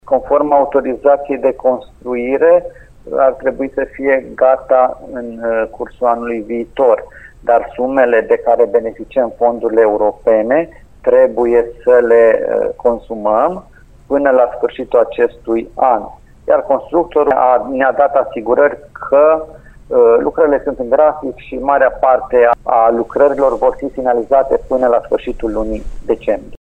Primarul interimar Bogdan Blidariu crede însă că actualul constructor ar putea să se încadreze în termen.